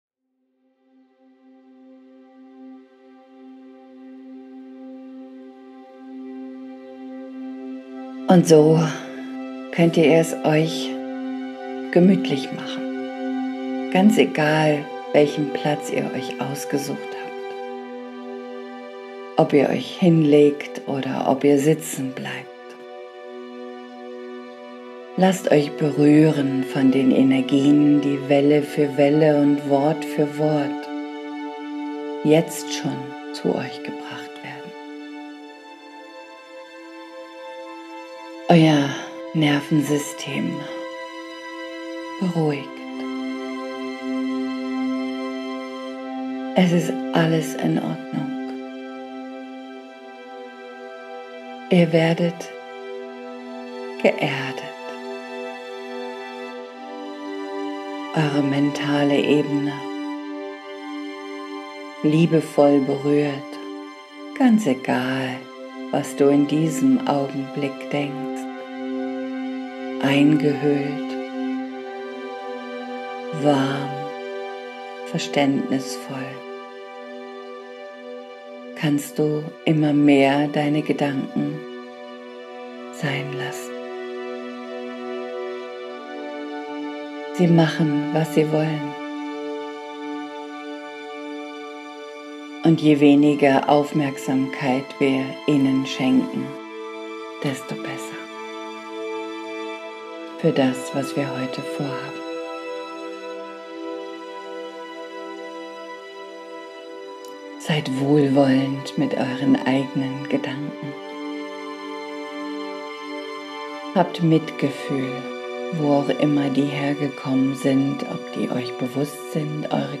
Meditation Christusbewusstsein und Innere Familie